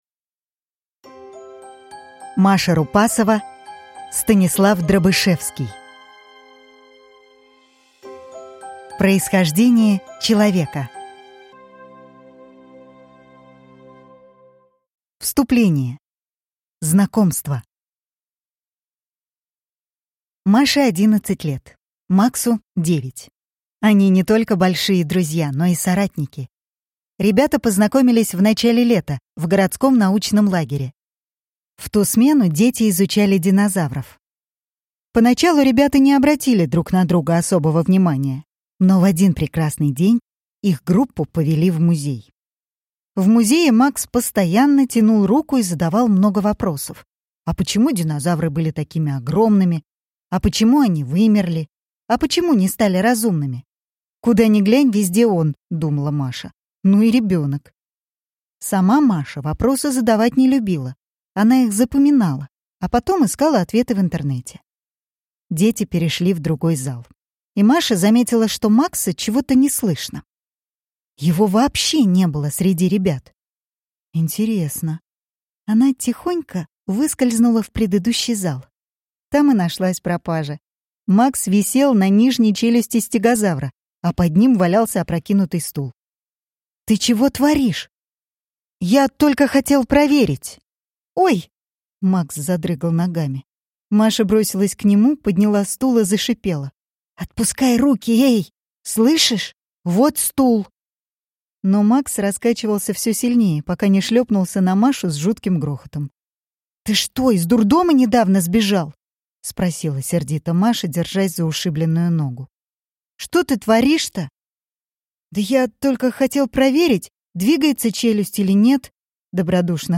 Аудиокнига Происхождение человека | Библиотека аудиокниг